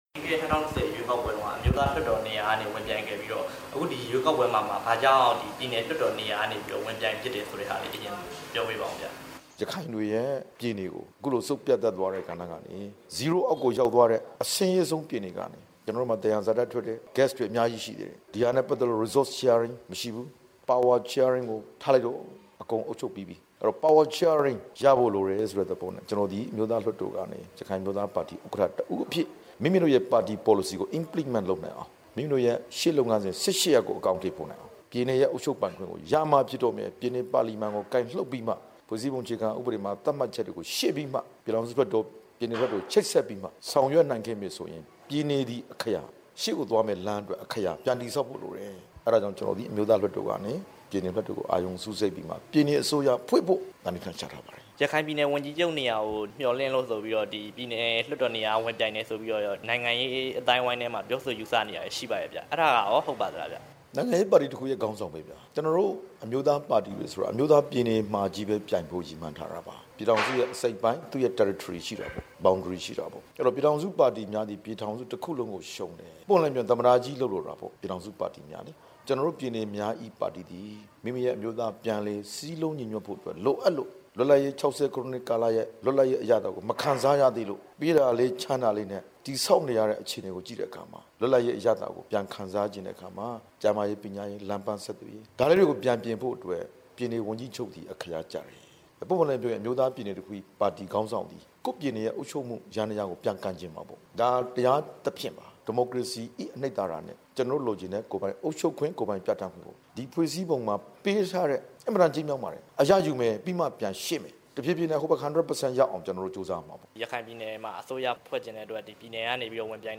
ဒေါက်တာအေးမောင် နဲ့ တွေ့ဆုံမေးမြန်းချက်